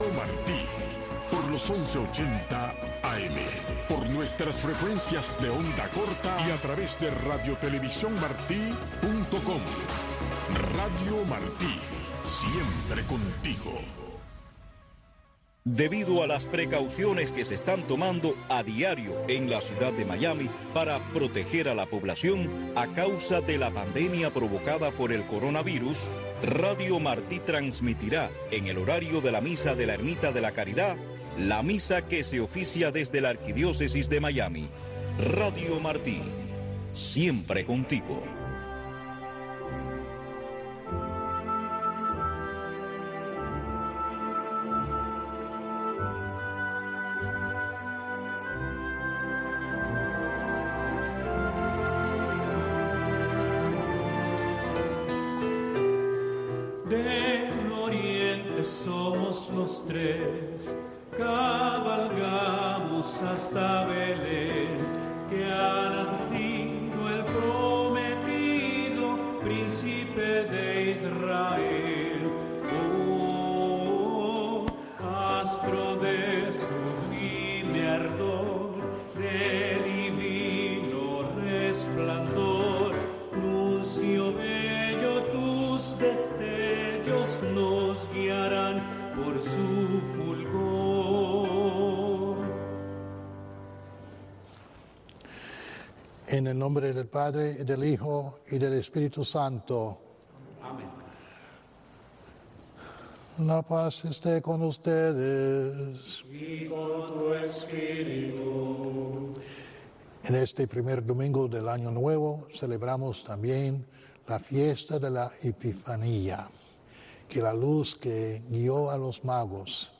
La Santa Misa
El Santuario Nacional de Nuestra Señor de la Caridad, más conocido como la Ermita de la Caridad, es un templo católico de la Arquidiócesis de Miami dedicado a Nuestra Señora de la Caridad, Patrona de Cuba.